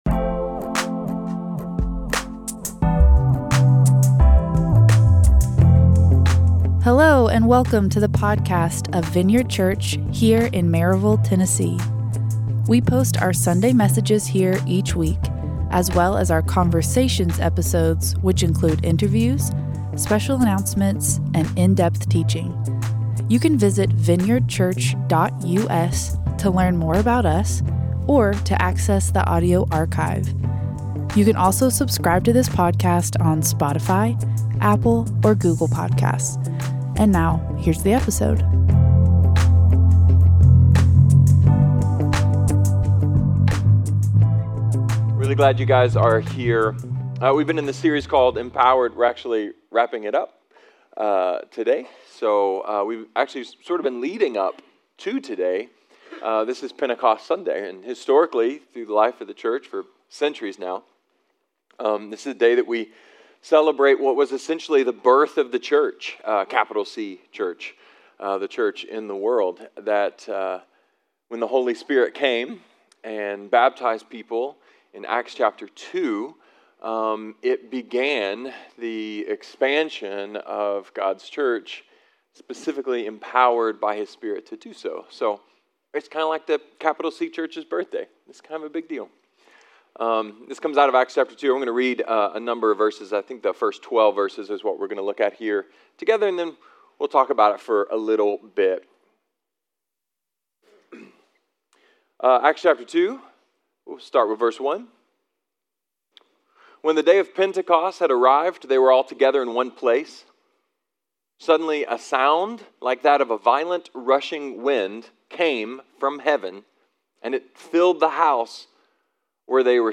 A sermon about the birth of the Church, the power of the Spirit, and the hope for the world.